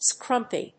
/skrˈʌmpi(米国英語), ˈskɹʌm.pi(英国英語)/